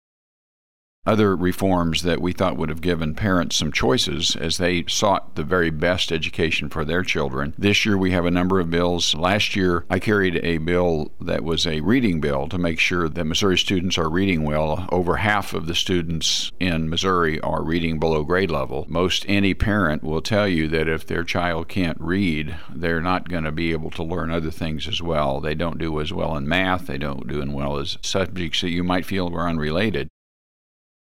JEFFERSON CITY — State Sen. Ed Emery, R-Lamar, discusses Senate Bill 349, legislation that seeks to require each local school district and charter school to have a policy for reading intervention plans for any pupils in grades kindergarten through four; Senate Bill 271, a measure that would transfer the authority of the State Board of Education and the Department of Elementary and Secondary Education to regulate charter schools to the Missouri Charter Public School Commission; and Senate Bill 160, which would establish the Missouri Empowerment Scholarship Accounts Program.